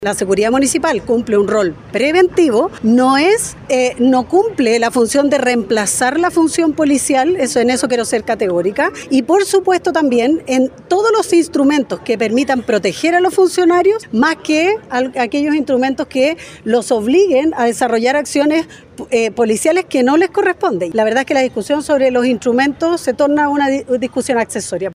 Puesto que el rol de ellos no es de policía, sino que preventivo, tal como mencionó la subsecretaria de Prevención del Delito, Carolina Leitao.